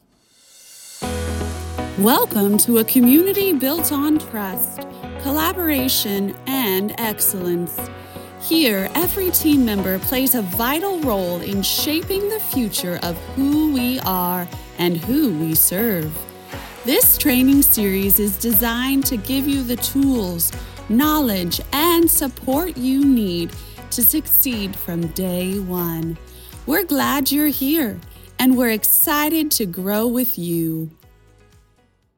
Corporate
English - Midwestern U.S. English
Operate a broadcast-quality home studio featuring a Shure SM7B microphone and Universal Audio Apollo Twin interface, delivering clean WAV audio with quick turnaround and professional editing.
My voice has a calm, reassuring quality that helps listeners feel both informed and encouraged.